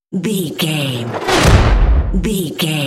Dramatic whoosh to hit trailer
Sound Effects
dark
futuristic
intense
tension
woosh to hit